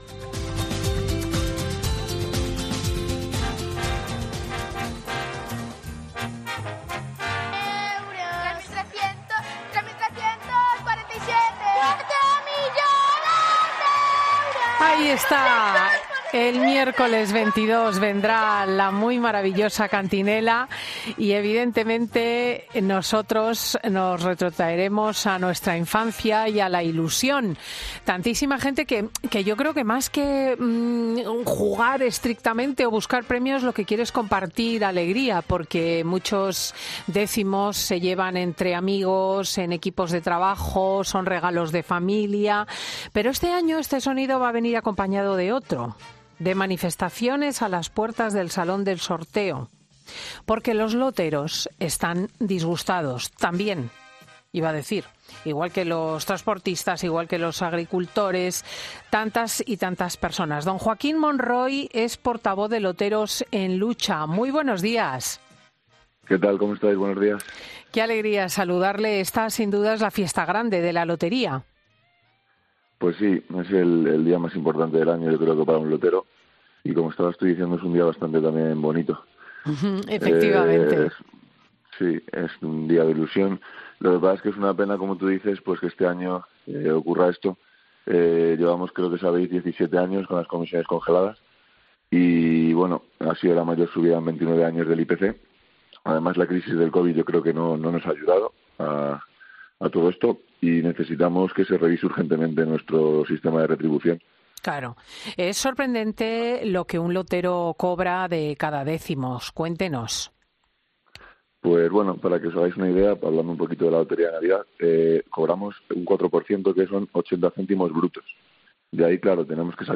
para reportajes y entrevistas en profundidad;